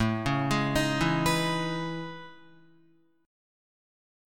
AM#11 chord